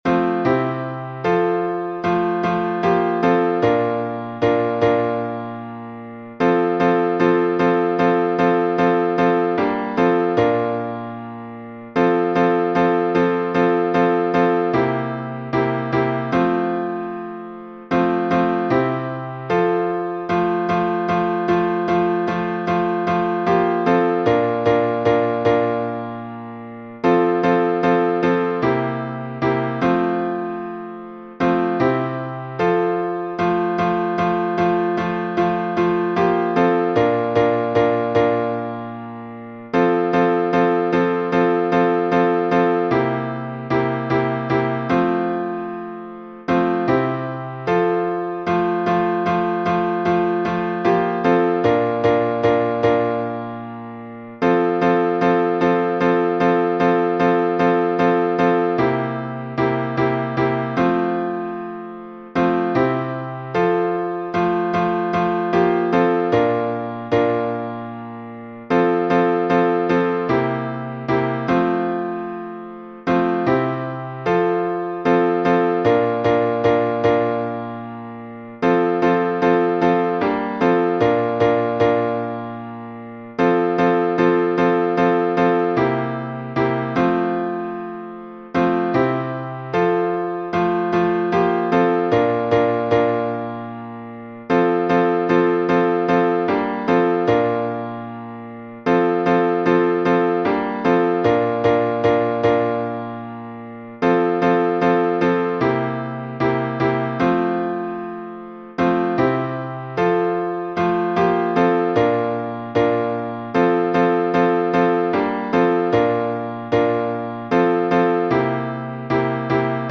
Греческий напев